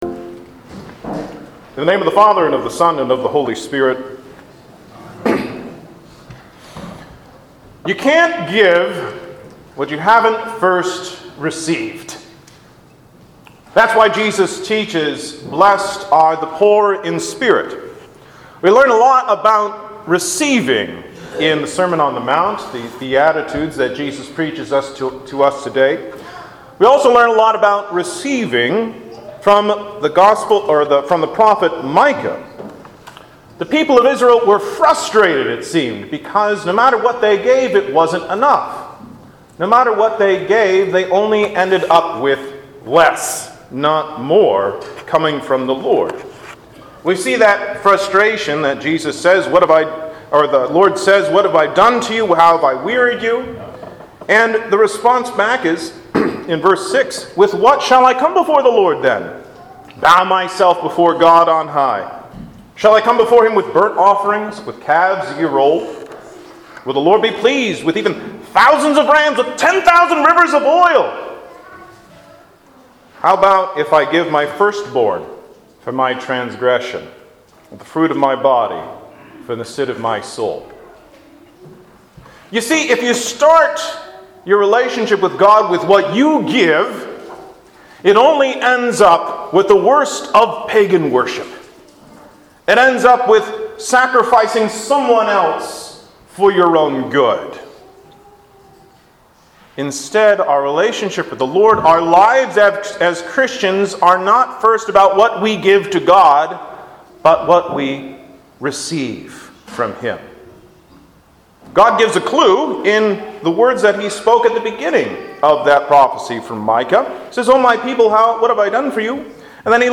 Sermons | Zion Lutheran Church LCMS